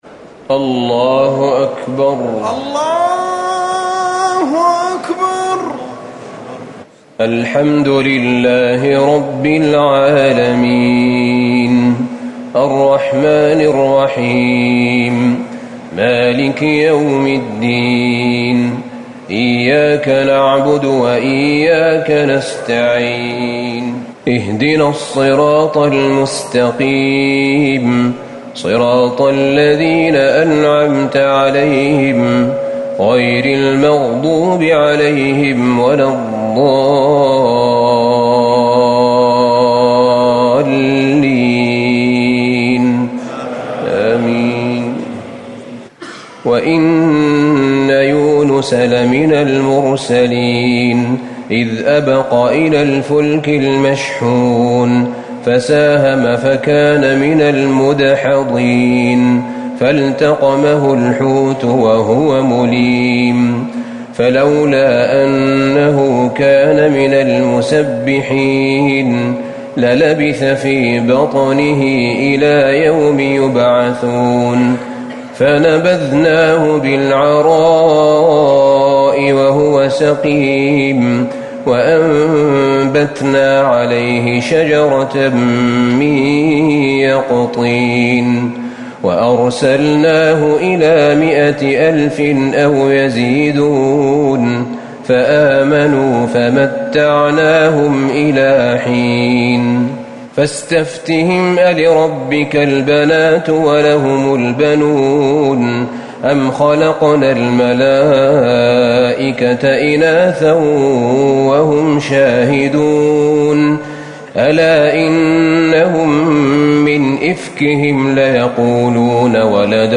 تراويح ٢٢ رمضان ١٤٤٠ من سورة الصافات ١٣٩ - الزمر ٣١ > تراويح الحرم النبوي عام 1440 🕌 > التراويح - تلاوات الحرمين